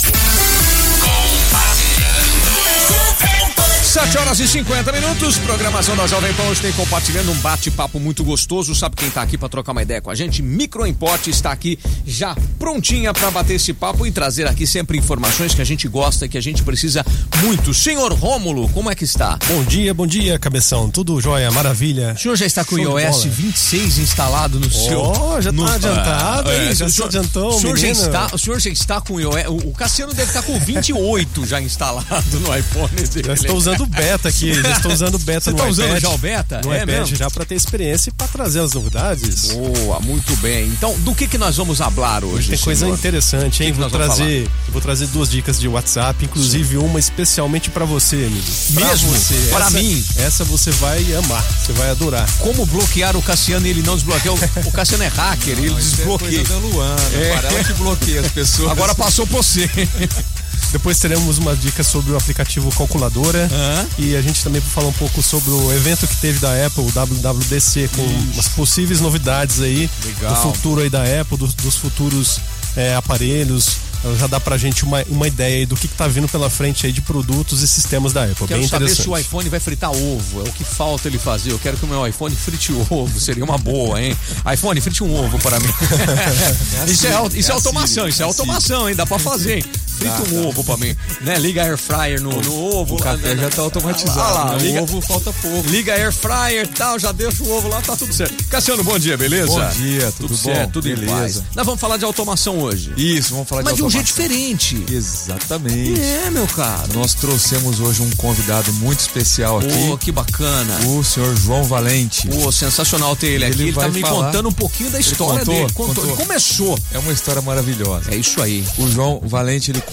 Micro Import, referência em Automação Residencial Apple e tecnologia em Ribeirão Preto desde 1994, ouça o podcast gravado na Jovem Pan com um episódio imperdível!